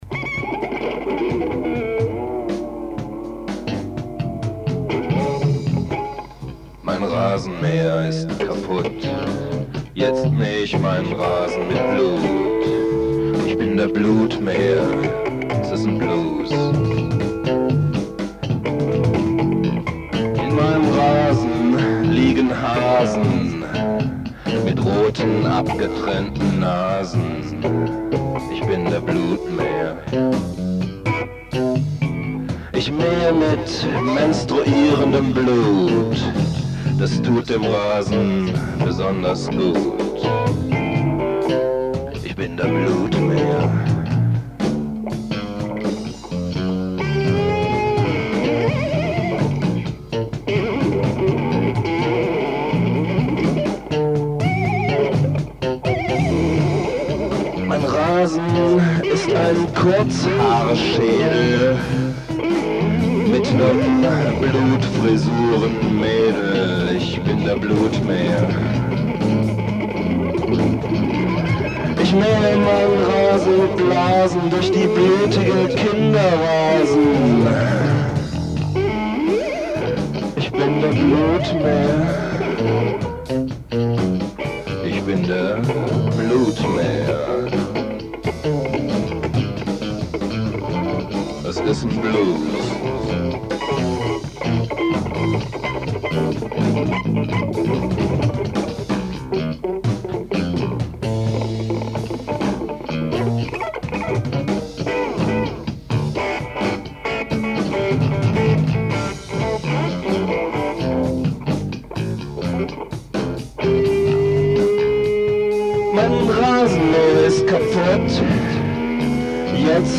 The result was pure dada.